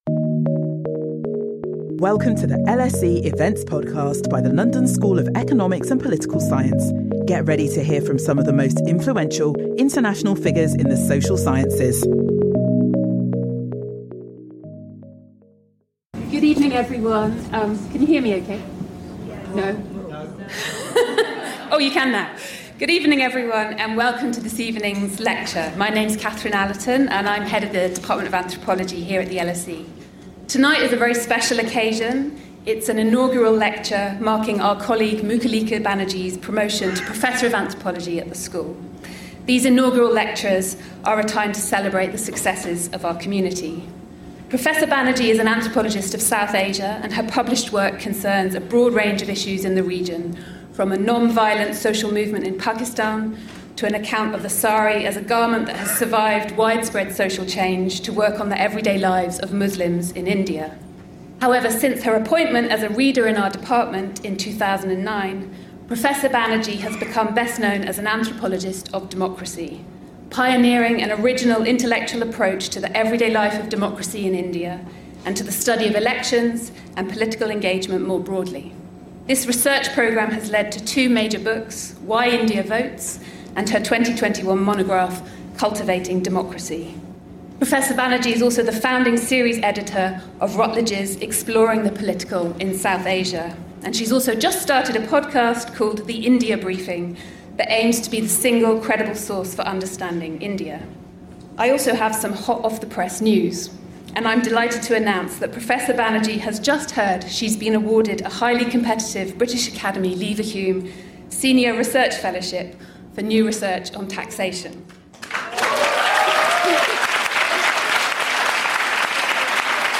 inaugural lecture